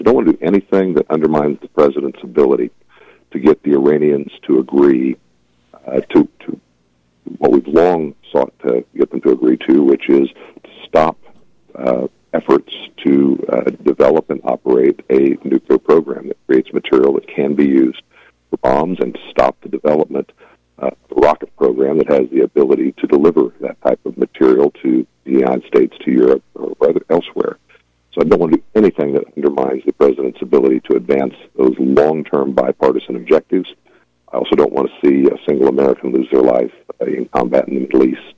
Hours before the attack started, Kansas Second District Congressman Derek Schmidt had his monthly appearance on KVOE’s Morning Show to talk about several policy points discussed by the president in his State of the Union speech, including relations with Iran.